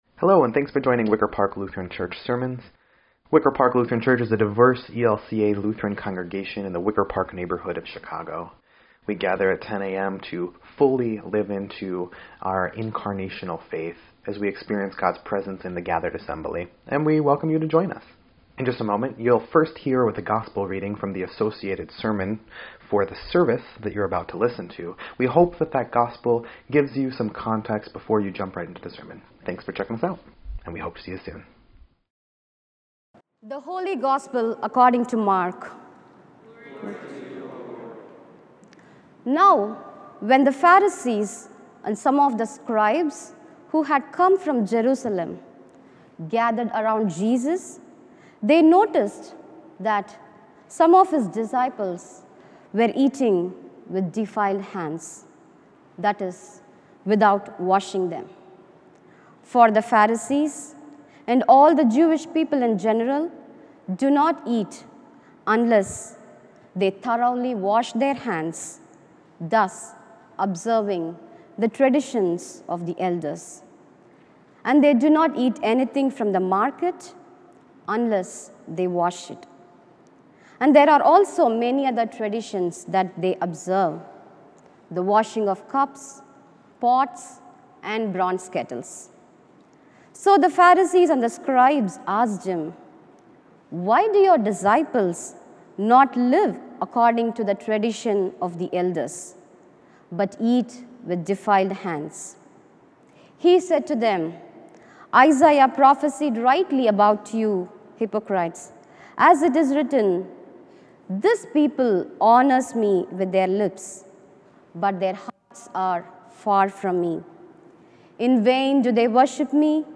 Fifteenth Sunday after Pentecost
9.1.24-Sermon_EDIT.mp3